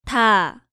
Category:Hangeul sounds